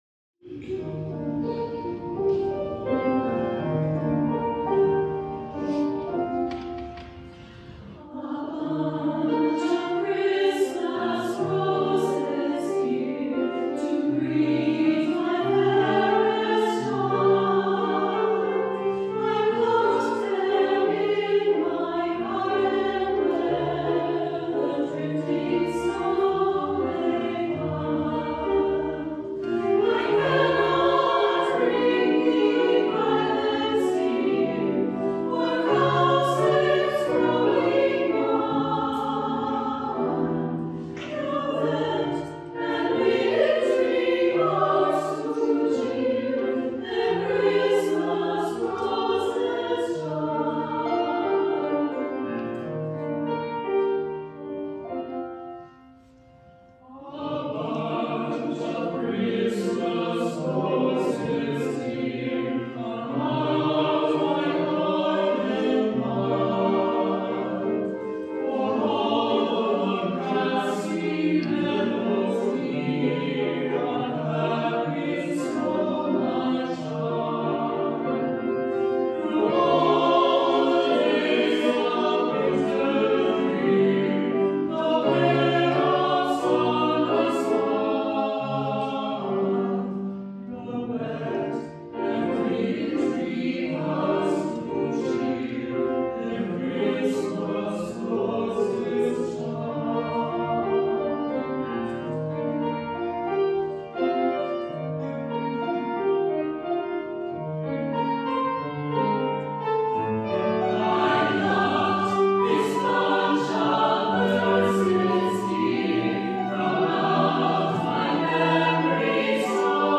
SSATB + Piano 3’15”
SSATB, Piano